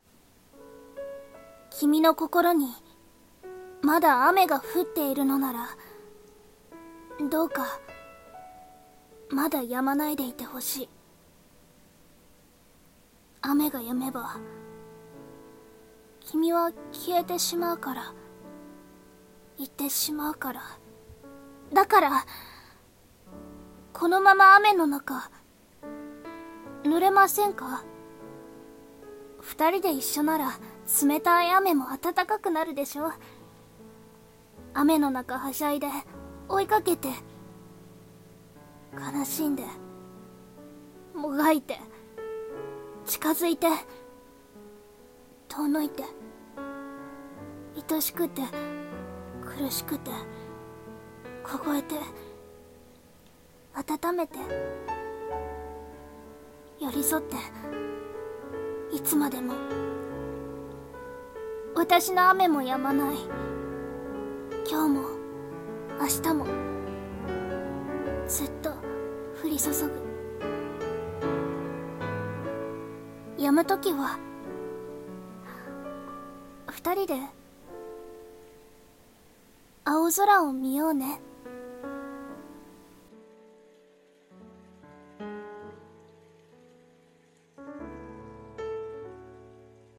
Beyond the rain】一人声劇